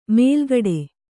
♪ mēlgaḍe